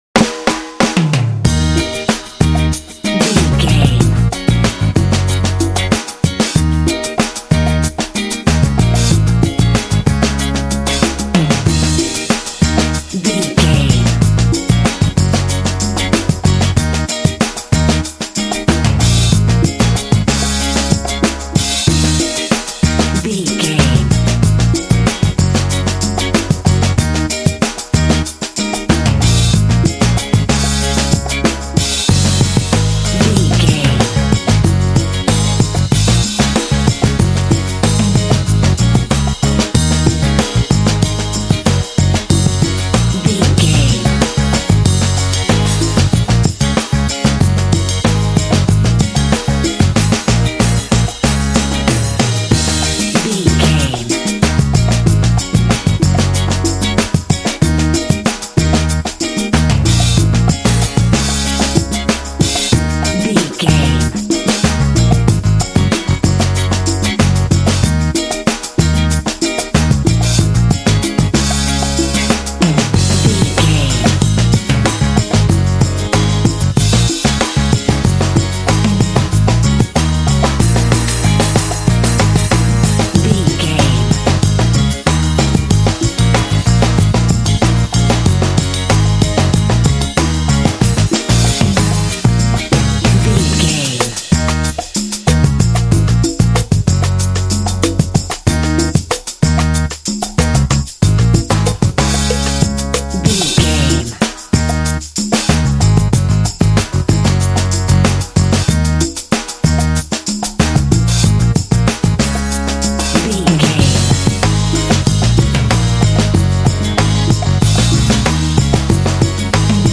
This cool and funky track is great for action games.
Epic / Action
Aeolian/Minor
E♭
groovy
driving
saxophone
drums
percussion
bass guitar
electric guitar